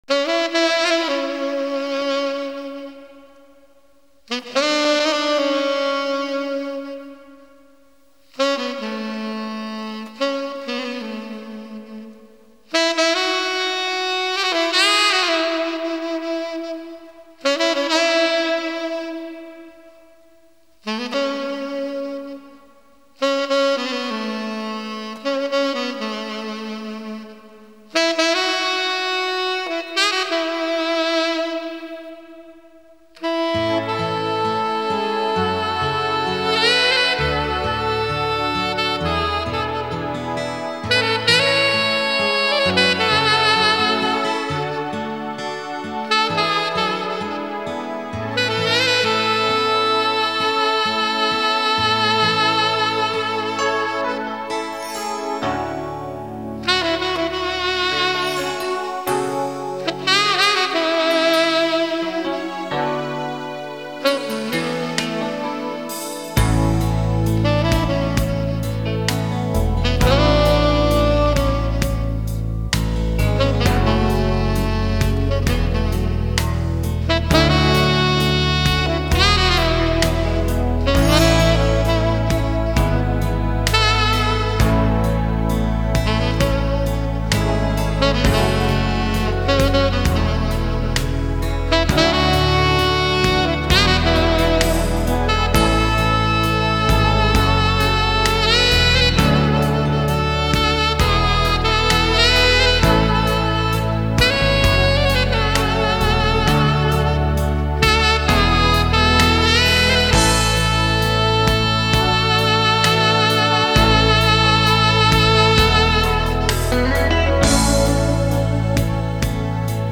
Галерея Музыка Саксофон